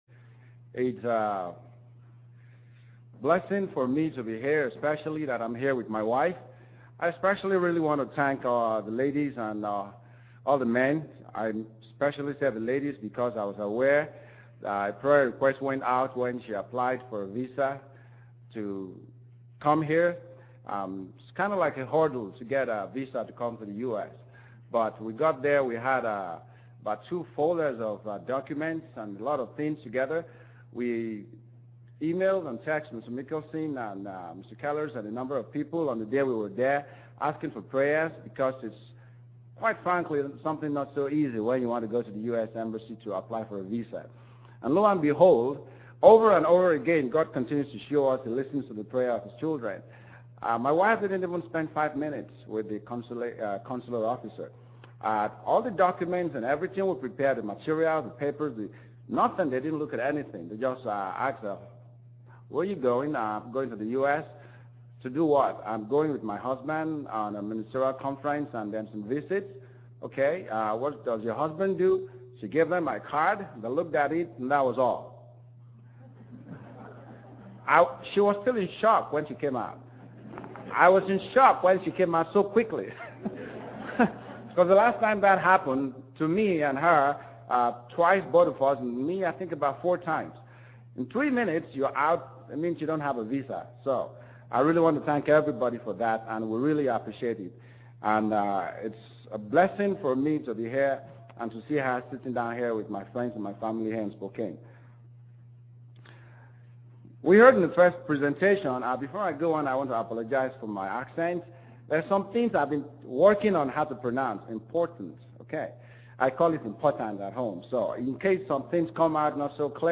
This message was given for a Kingdom of God seminar.
Given in Spokane, WA
UCG Sermon Studying the bible?